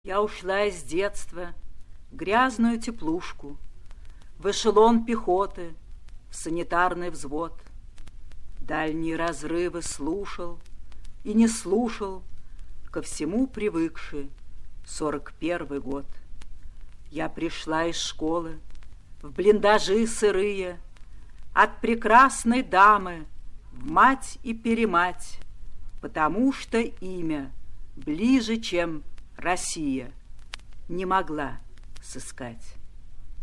1. «Юлия Друнина – Я ушла из детства в грязную теплушку (читает автор)» /
yuliya-drunina-ya-ushla-iz-detstva-v-gryaznuyu-teplushku-chitaet-avtor